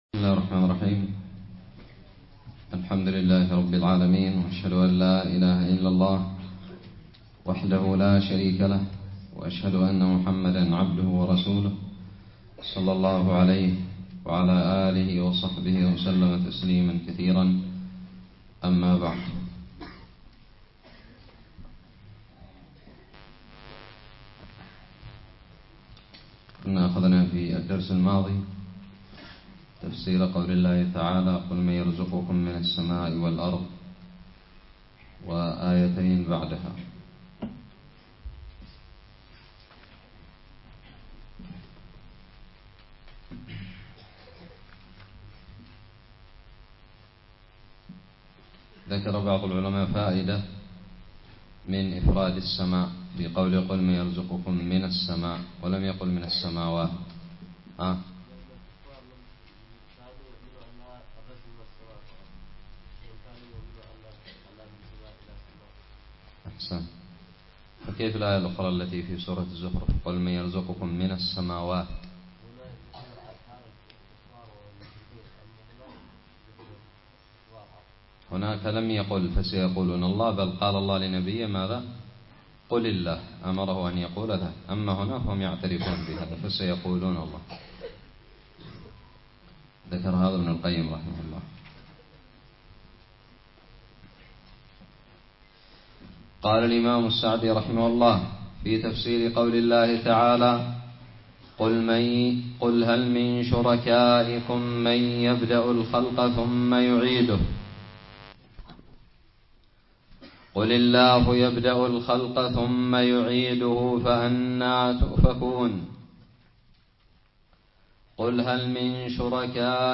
الدرس الرابع عشر من تفسير سورة يونس
ألقيت بدار الحديث السلفية للعلوم الشرعية بالضالع